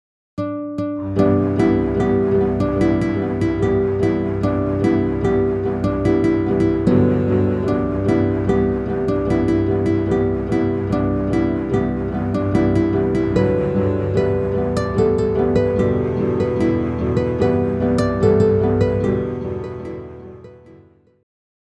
Documentary Soundtrack
Based on Folk Music of the Andes
Orchestral Album